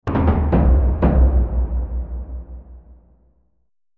دانلود آهنگ نبرد 17 از افکت صوتی انسان و موجودات زنده
جلوه های صوتی